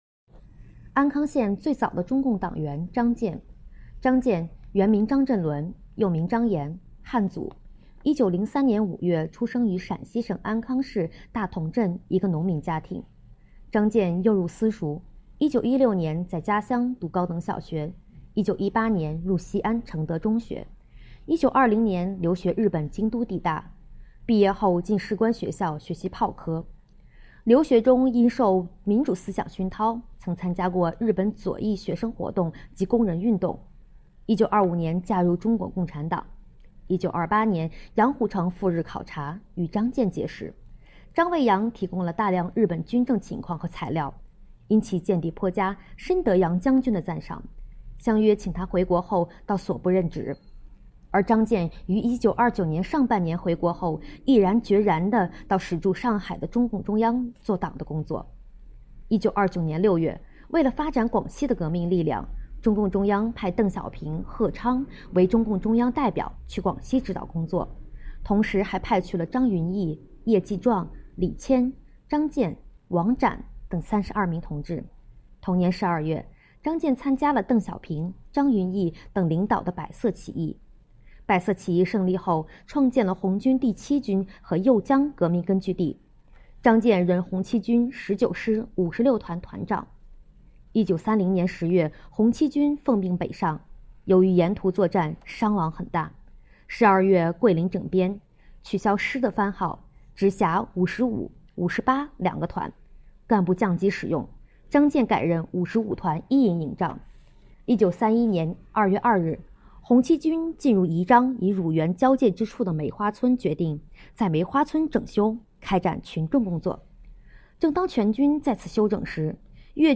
【红色档案诵读展播】安康最早的共产党员章健